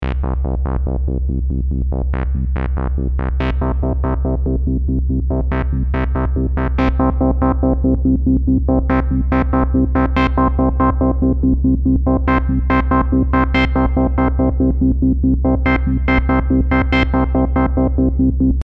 Example a step sequencer modulating the cut-off parameter of a lowpass filter.
Mod-Cut-Sequencer_-3dB.mp3